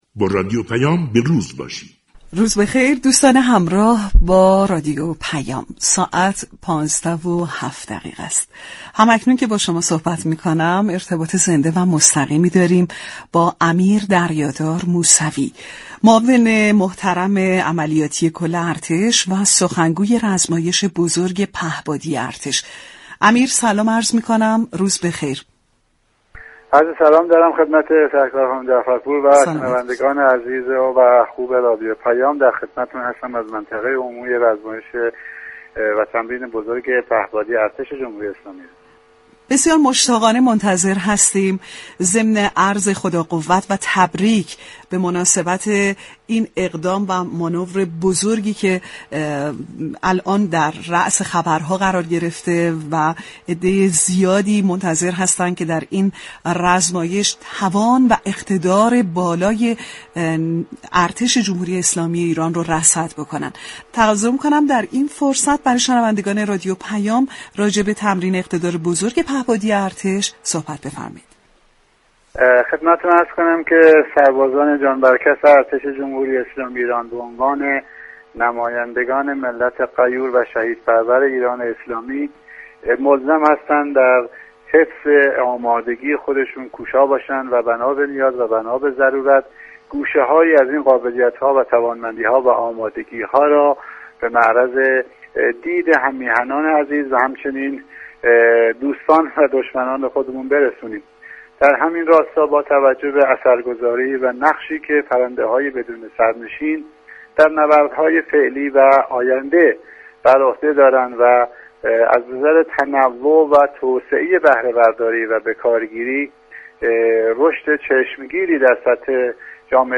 امیر دریادار سید محمود موسوی، در گفتگو با معاون عملیات ارتش در گفتگو با رادیو پیام: